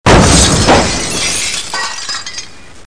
shatter.mp3